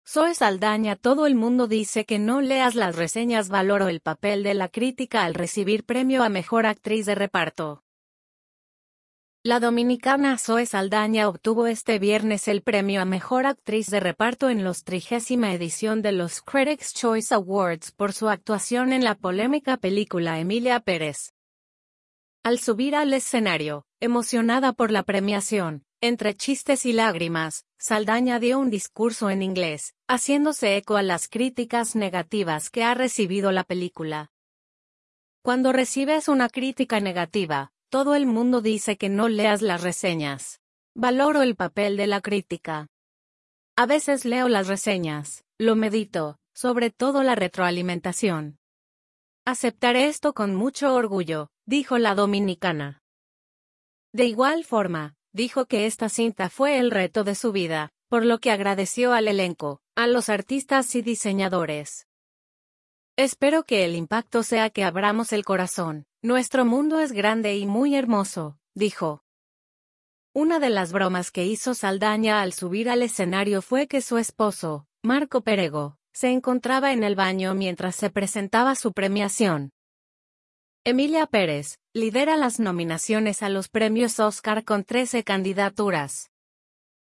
Al subir al escenario, emocionada por la premiación, entre chistes y lágrimas, Saldaña dio un discurso en inglés, haciéndose eco a las críticas negativas que ha recibido la película.